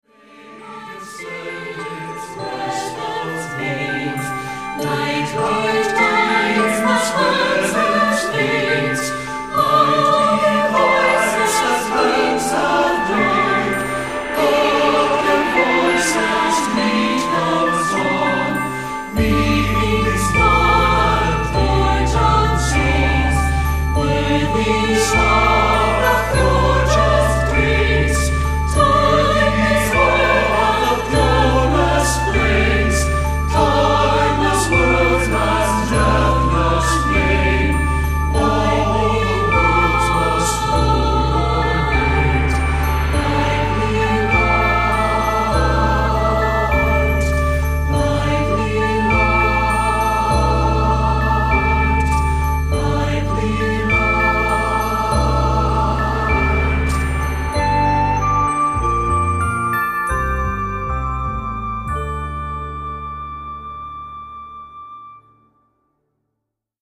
Accompaniment:      Keyboard, Flute
Music Category:      Christian
Flute ad lib.Ê